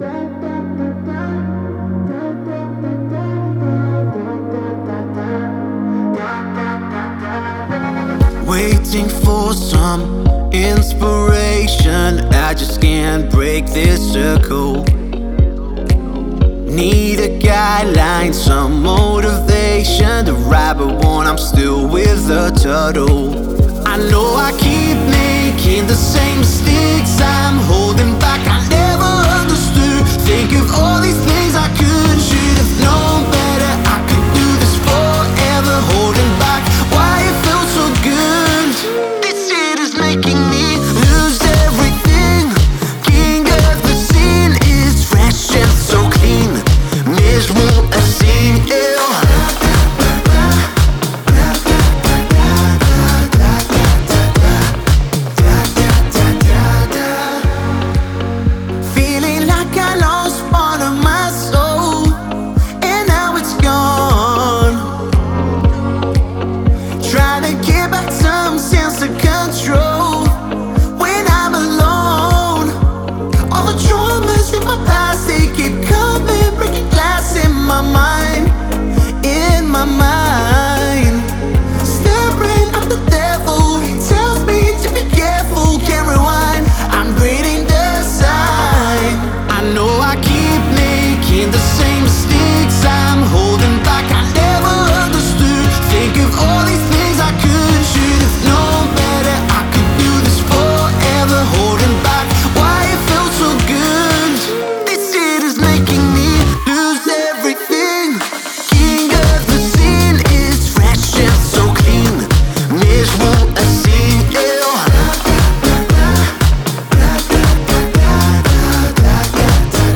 это энергичная трек в жанре поп-музыки с элементами хип-хопа